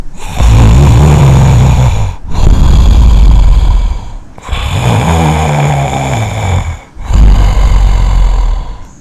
earrape_ZMOfYnO.mp3